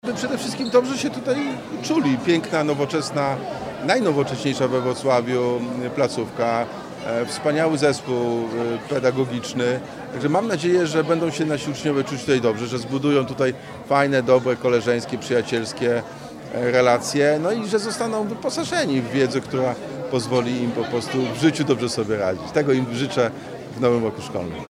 Prezydent Jacek Sutryk zaznaczył, że inwestowanie w edukację młodych ludzi jest kluczowe dla przyszłości miasta.